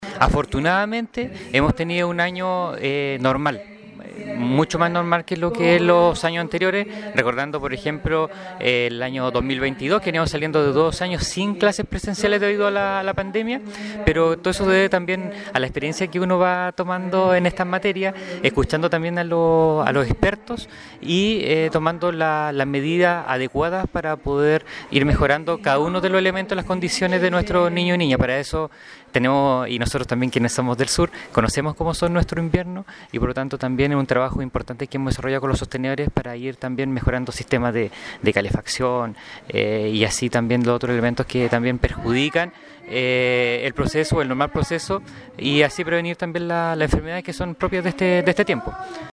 A nivel local, el director provincial de educación, Giovanni Hernán Aravena, señaló que existen planes si se hace necesario el adelantar el período de vacaciones, pero por el momento se mantiene lo planificado